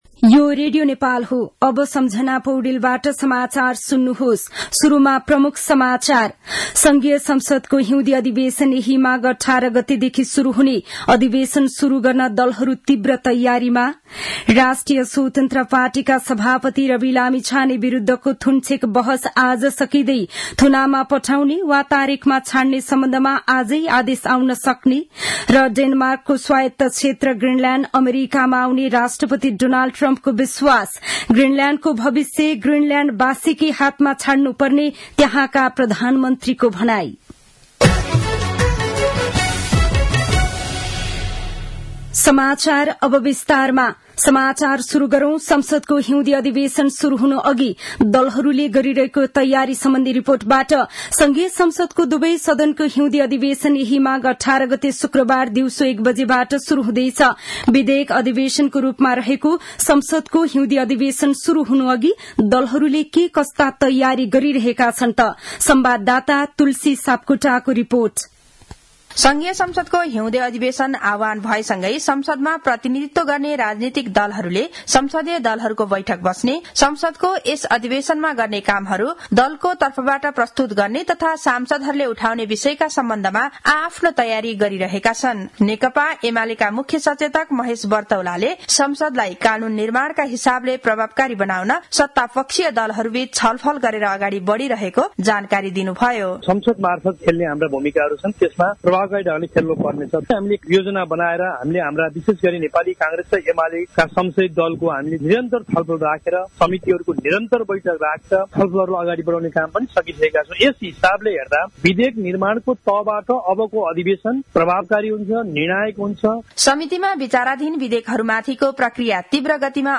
दिउँसो ३ बजेको नेपाली समाचार : १४ माघ , २०८१